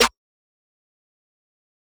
Metro Snares [Best].wav